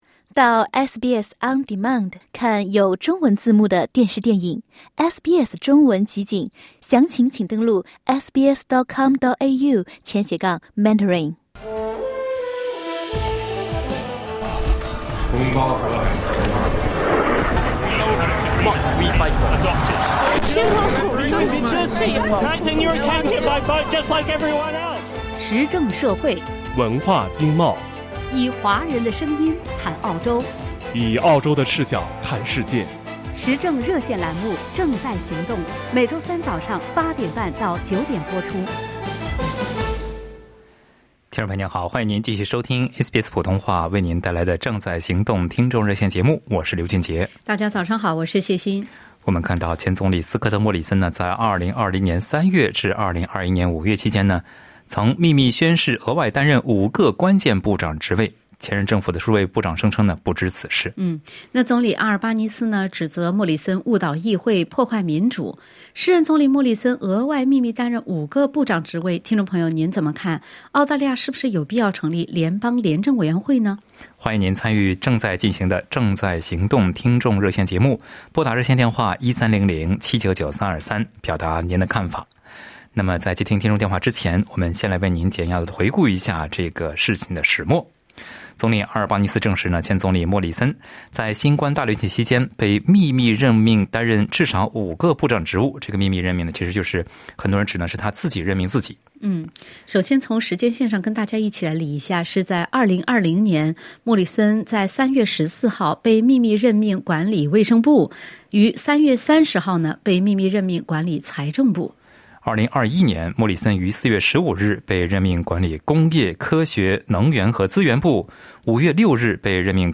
热线听众仅代表其个人观点，不代表本台立场，仅供参考。